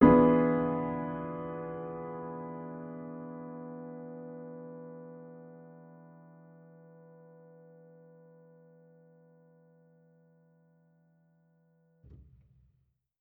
Index of /musicradar/jazz-keys-samples/Chord Hits/Acoustic Piano 2
JK_AcPiano2_Chord-E7b9.wav